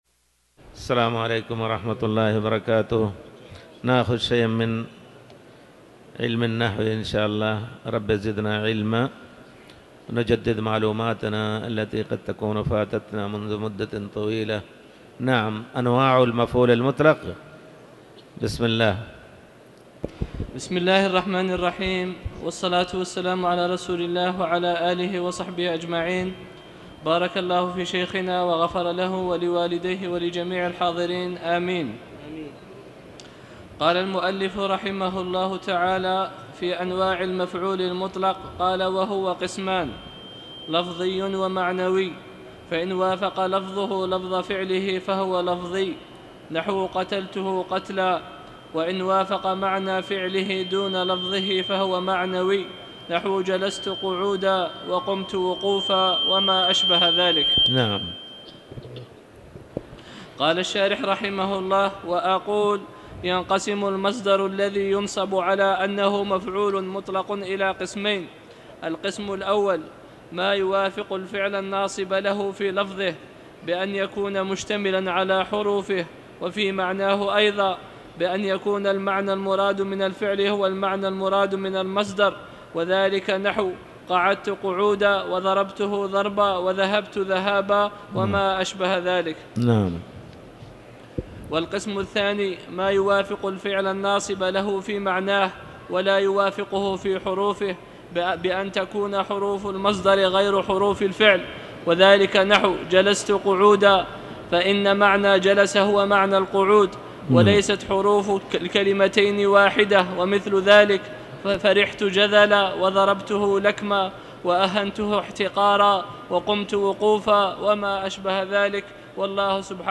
تاريخ النشر ١٣ جمادى الأولى ١٤٤٠ هـ المكان: المسجد الحرام الشيخ